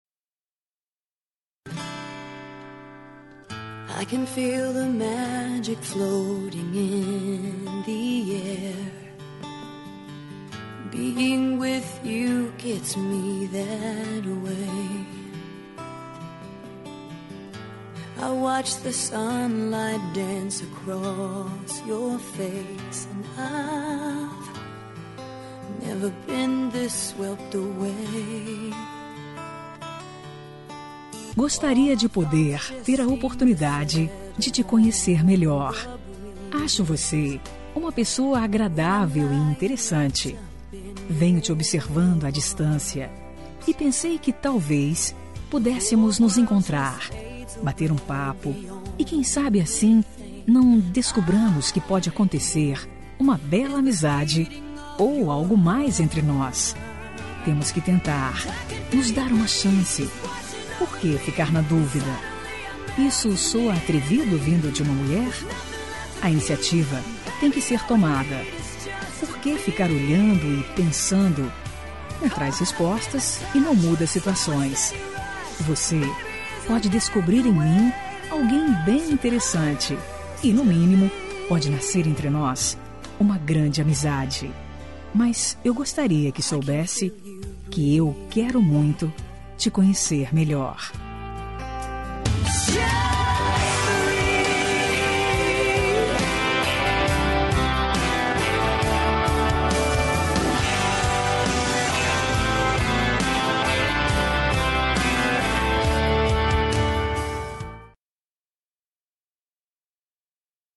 Telemensagem de Paquera – Voz Feminina – Cód: 2147 – Ousada
Paquera Ousada fem 2147.mp3